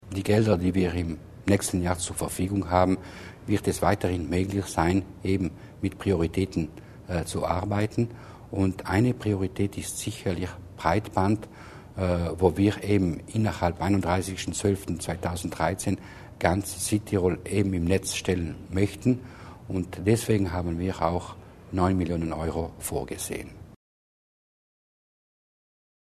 Landesrat Mussner zur Priorität fürs Breitband